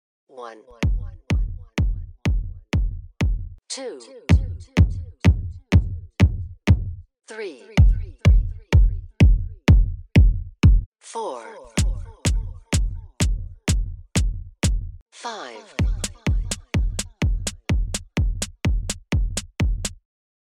OT master, PT slave…set up as you suggested, 4 steps…
• 1 kick on its own OT
• 2 same kick sample on OT and PT [noticabley not lining up, not sounding good. crappy] * image 1
• 3 same kick post edited to line up exactly, still sounds bad description below ** image 2
• 4 two different samples playing at same time. sounds great, on time!
• 5 two samples how you’d probably use them, SOUNDS AWES…cant hear an issue.